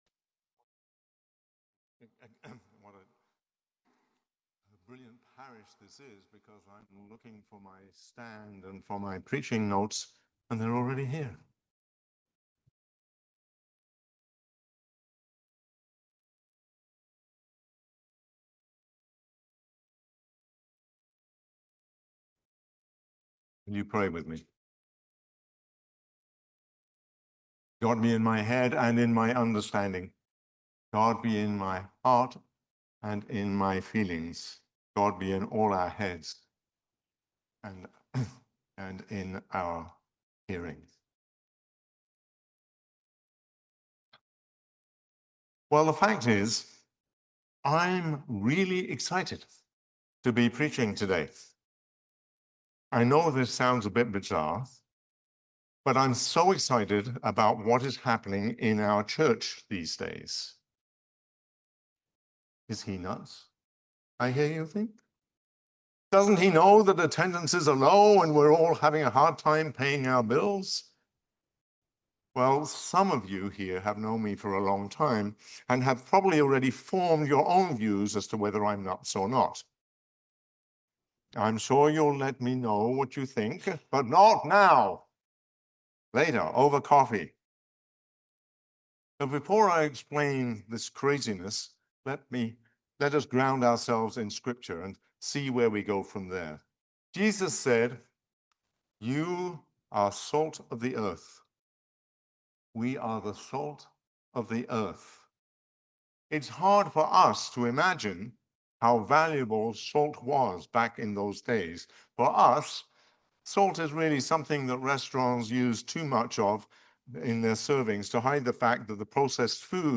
Sermon on the Fifth Sunday after Epiphany